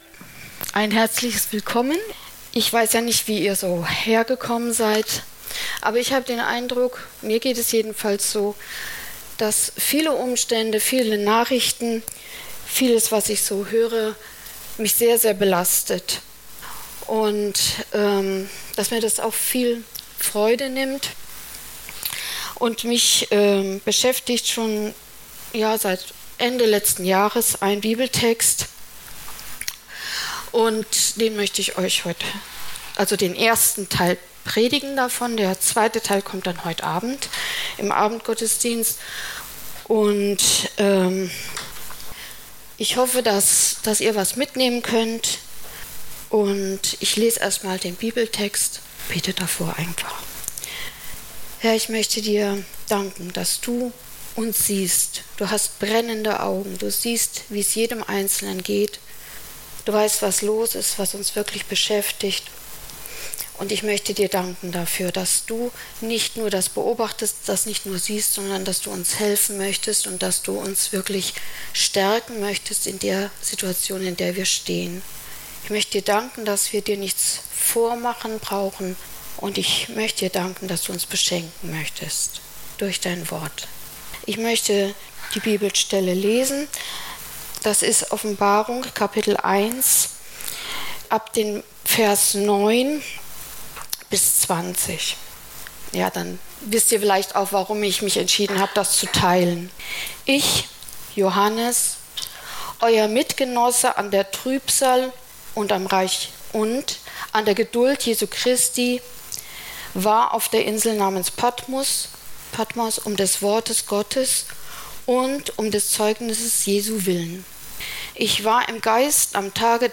Predigt vom 19. Februar 2023 – Süddeutsche Gemeinschaft Künzelsau